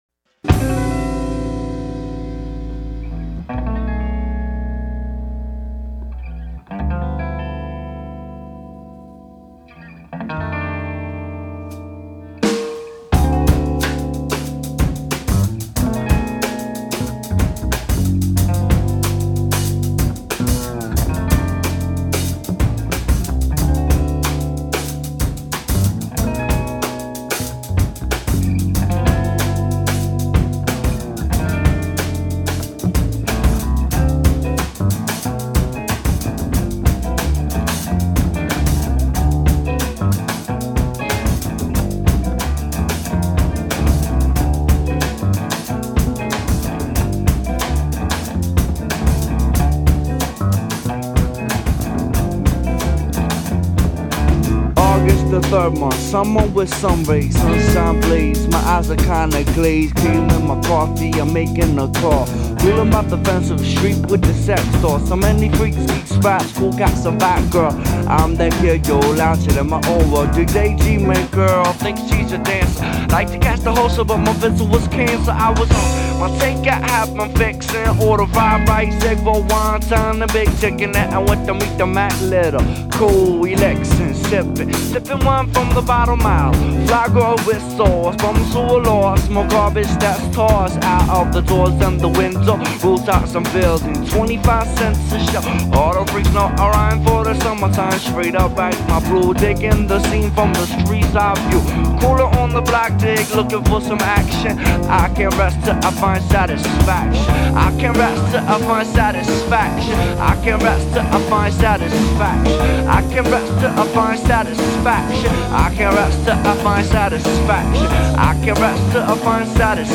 Organic and sun-drenched, this is a perfect opener.